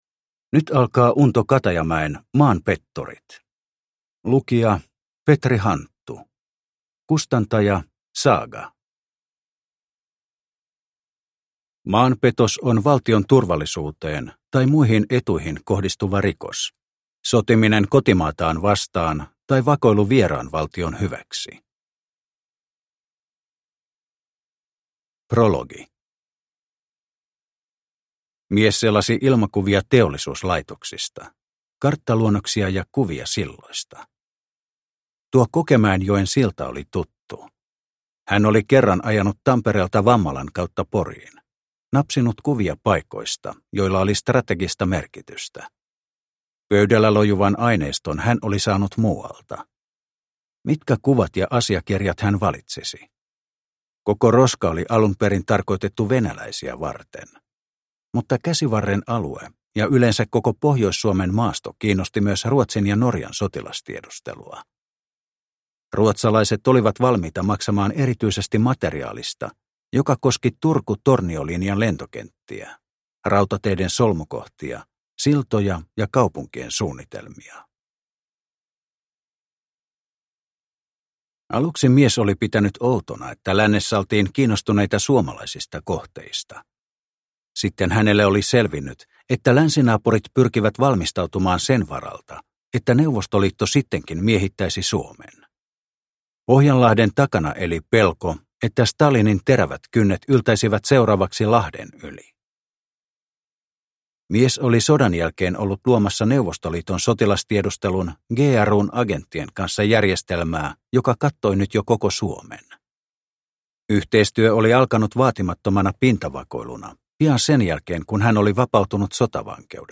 Maanpetturit – Ljudbok – Laddas ner